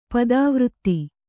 ♪ padāvřtti